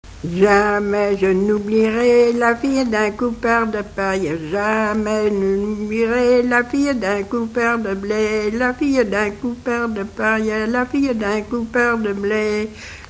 Divertissements d'adultes - Couplets à danser
Pièce musicale inédite